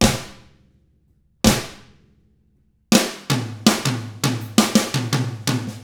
164ROCK I1-L.wav